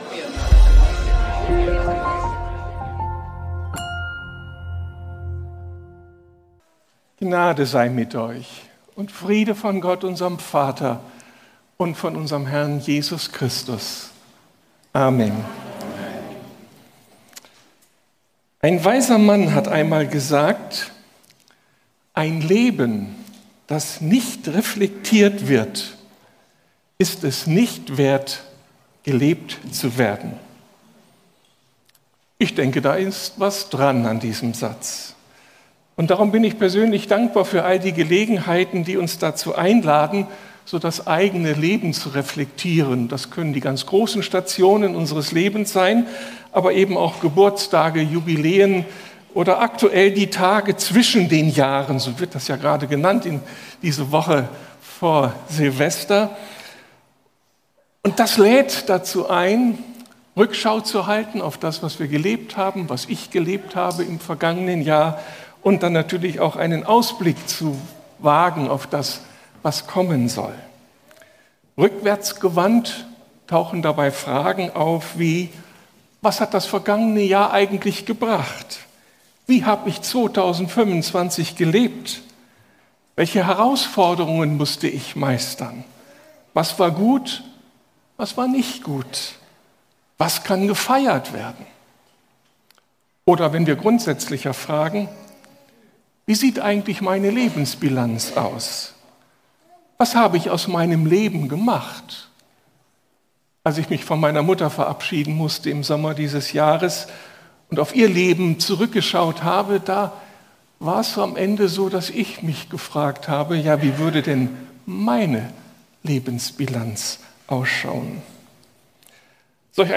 Eine neue Perspektive ~ Predigten der LUKAS GEMEINDE Podcast